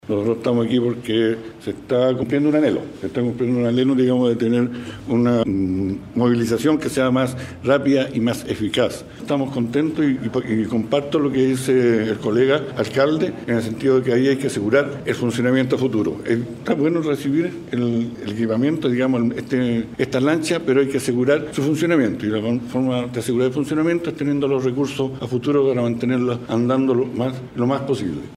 Por su parte, el alcalde de Quellón, Claudio Barudy, destacó que la incorporación de estas ambulancias marítimas fortalecerá la capacidad de respuesta ante emergencias, especialmente en condiciones climáticas adversas, mejorando la atención de los pacientes y el trabajo de los equipos de salud, pero también se sumó al requerimiento de su par de Quinchao.